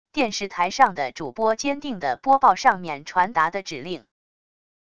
电视台上的主播坚定地播报上面传达的指令wav音频